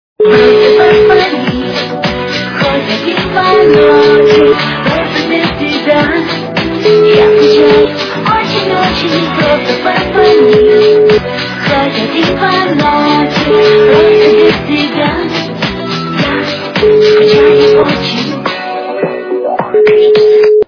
- русская эстрада
При заказе вы получаете реалтон без искажений.